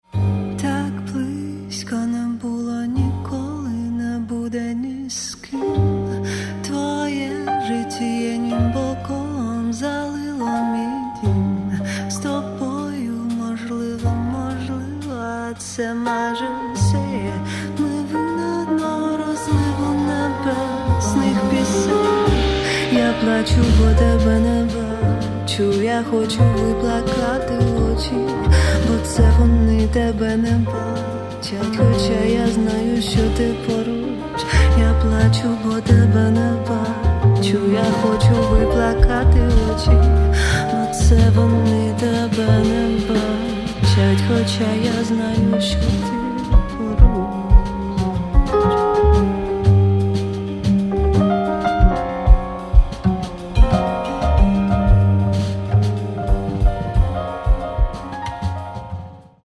Каталог -> Джаз и около -> Джаз-вокал
vocals
trumpet
keys
doublebass
drums